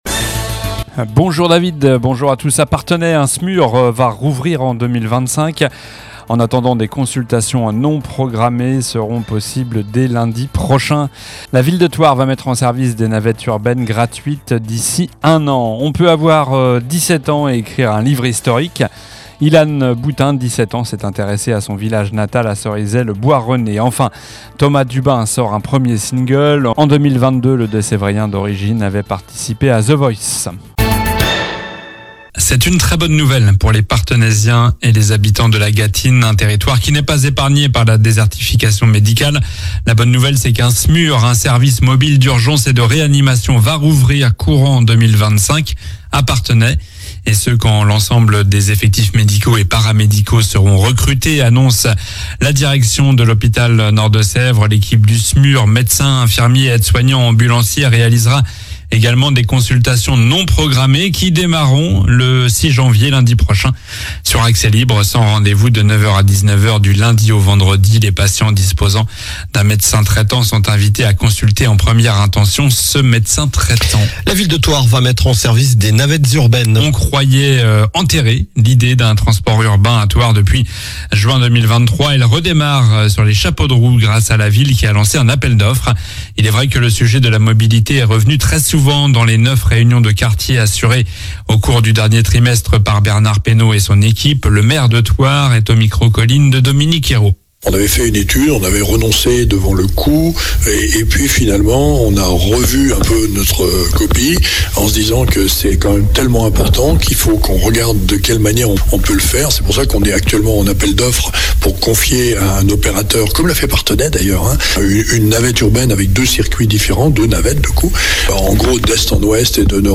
Journal du vendredi 03 janvier (matin)